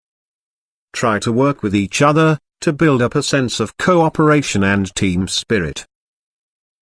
You will hear a sentence.